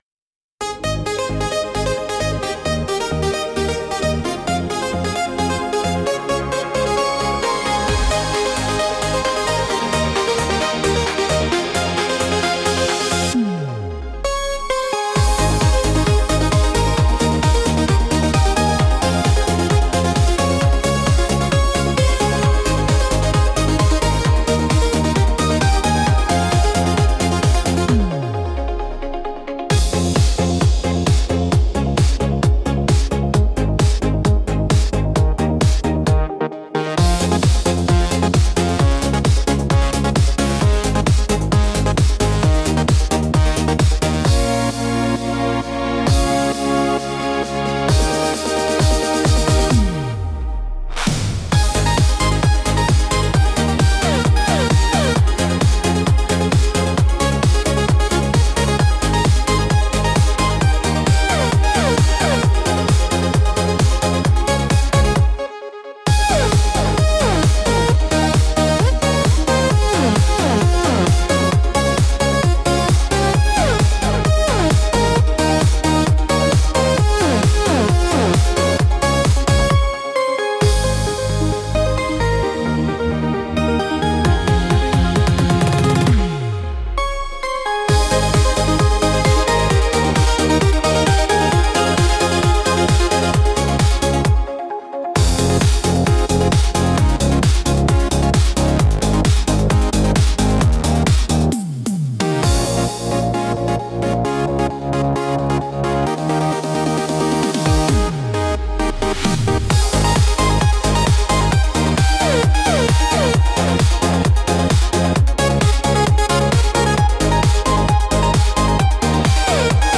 Instrumental - Vaporwave Cruise 2.45